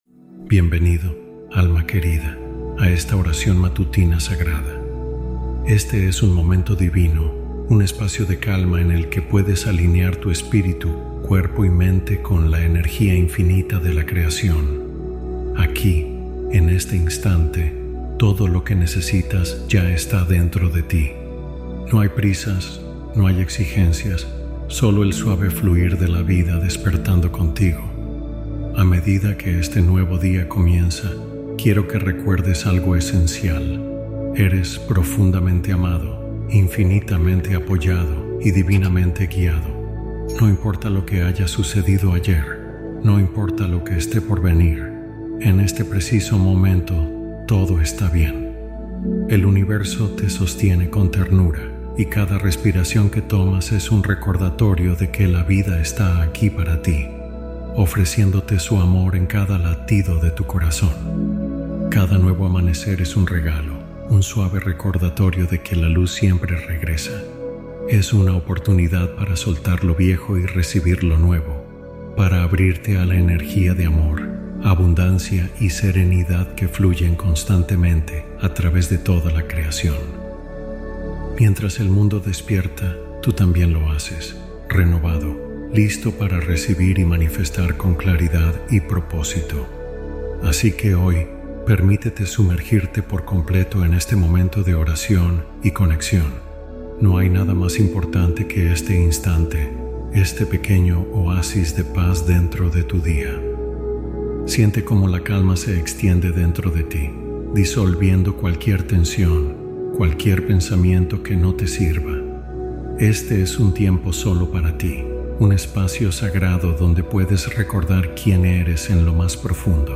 Meditación Matutina Para Alineación y Abundancia